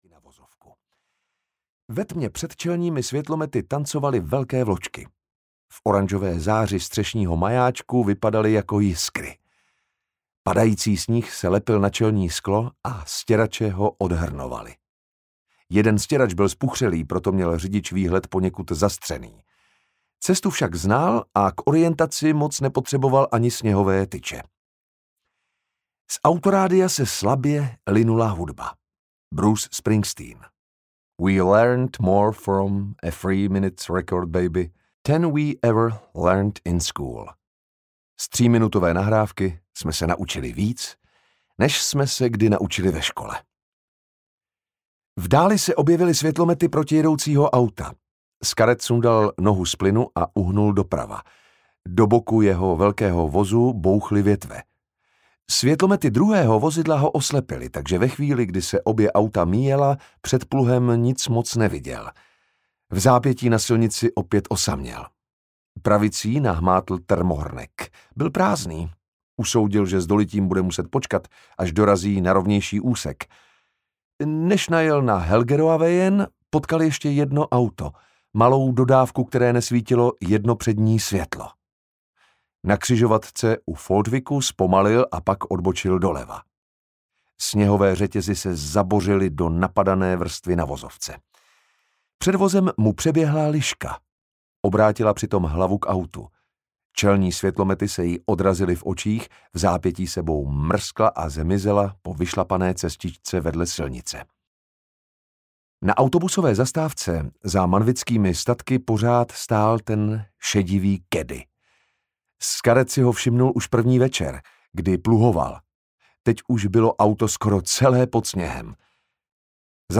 Bez hranic audiokniha
Ukázka z knihy